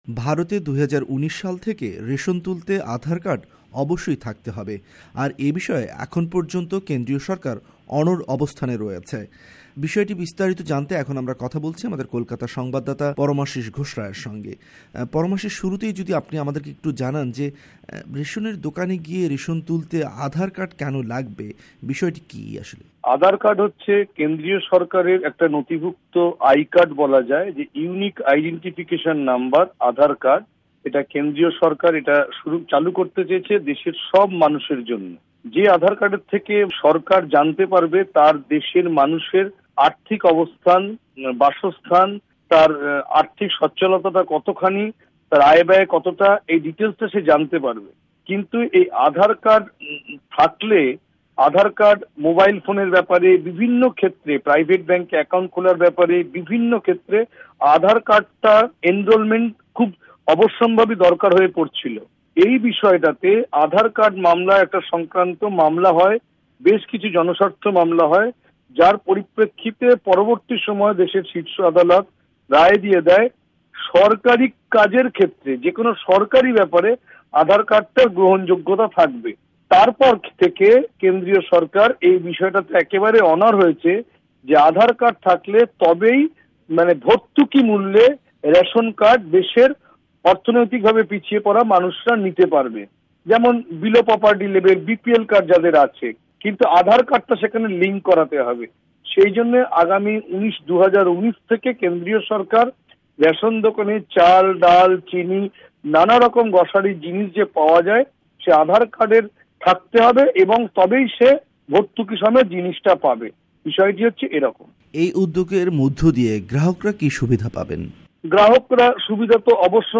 ওয়াশিংটন ষ্টুডিও থেকে কথা বলেছেন